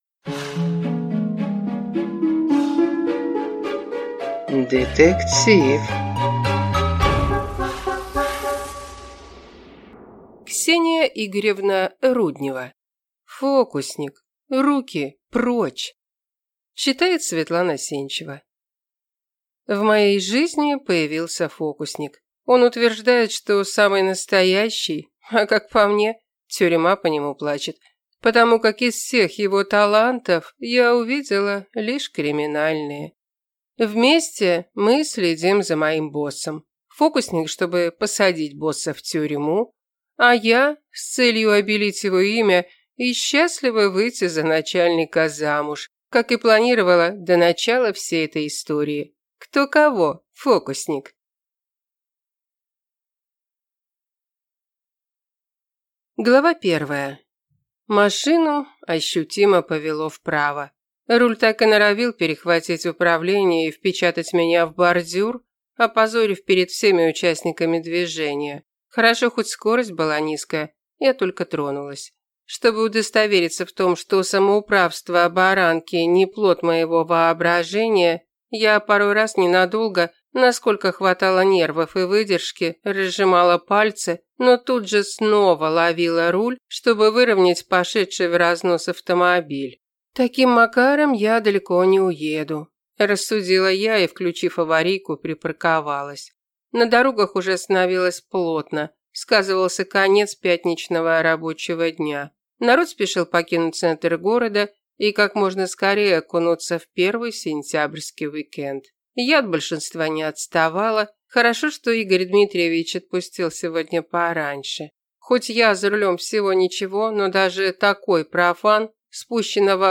Аудиокнига Фокусник, руки прочь!